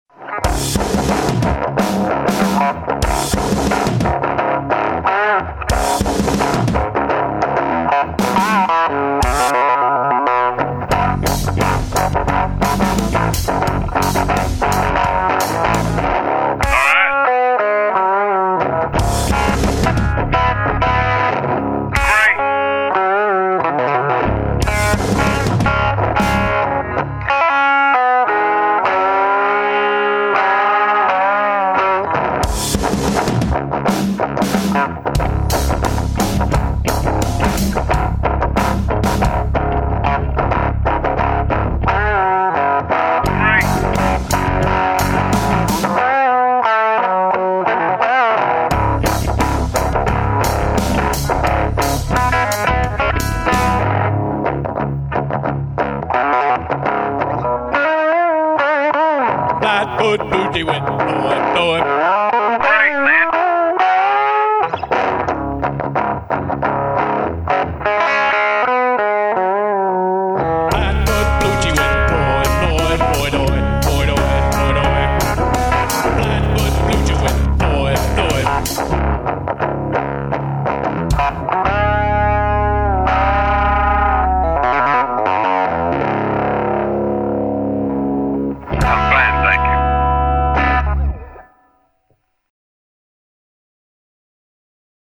Also thrown in for good measure, the semi-instrumental, "Red Zone Bar-B-Q,"